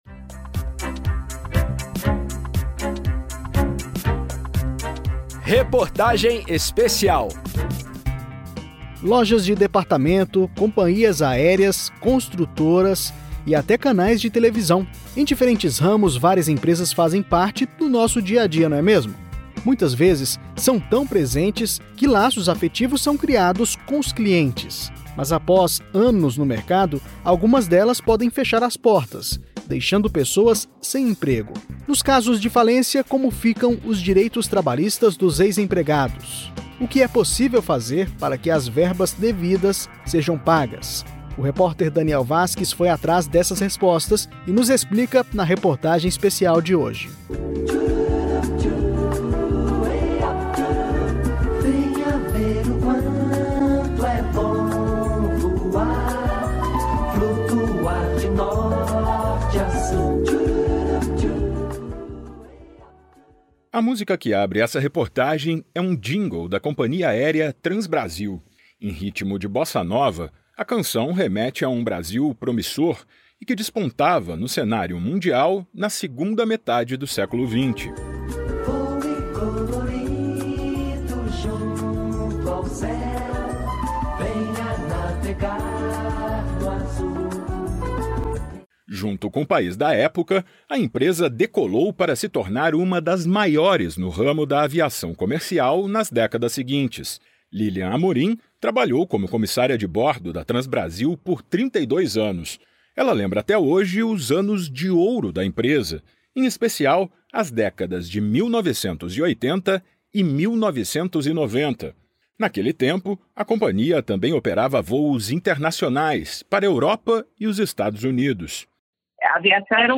A Lei nº 11101 de 2005, chamada de lei de falências, prioriza o pagamento dos créditos trabalhistas a empregados. Saiba na reportagem especial o que a legislação estabelece sobre o assunto.